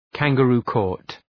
kangaroo-court.mp3